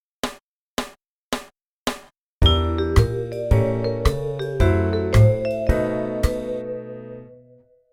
practicing jazz piano
2 minor 25 key centres
And here are two pairs of minor II – V’s, in which I use their tonic harmonic minor scales for my solo.